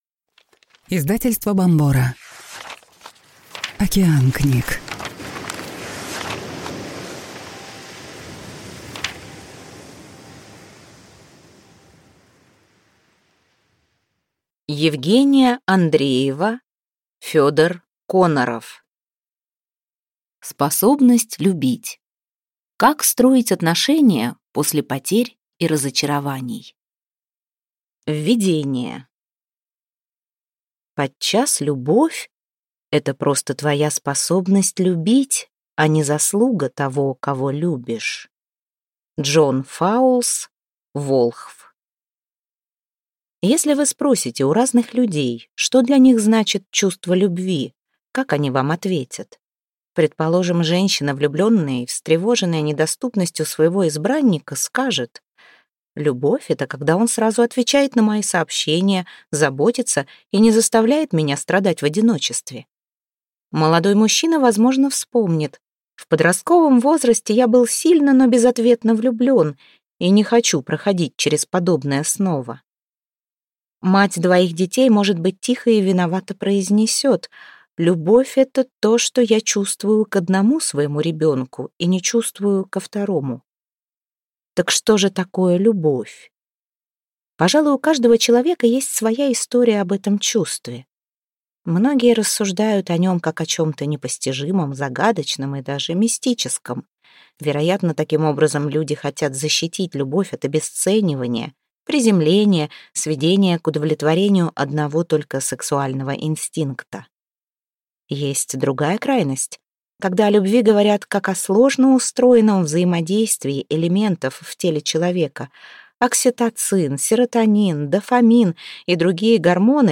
Аудиокнига Способность любить. Как строить отношения после потерь и разочарований | Библиотека аудиокниг